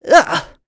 Lucias-Vox_Damage_03.wav